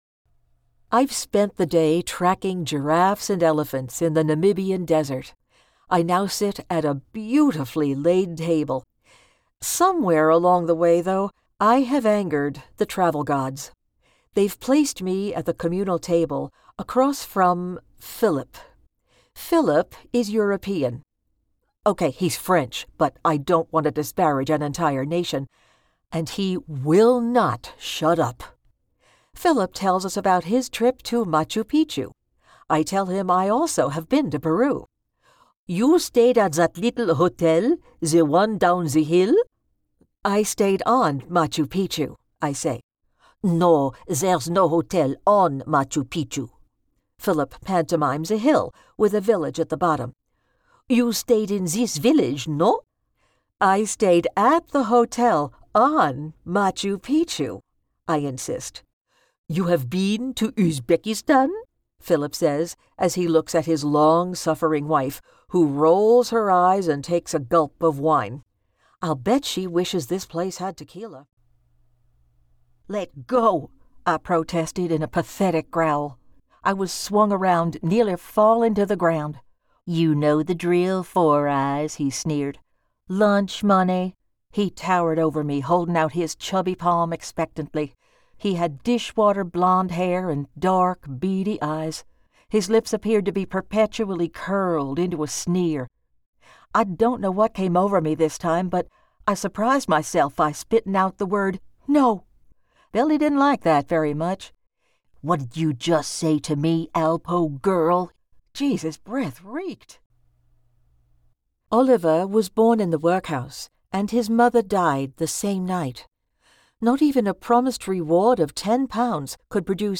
Female
Adult (30-50), Older Sound (50+)
My voice is friendly & warm, engaging & trustworthy, professional, dynamic, bubbly & entertaining.
Audiobooks
Words that describe my voice are Engaging, Professional, Dynamic.
All our voice actors have professional broadcast quality recording studios.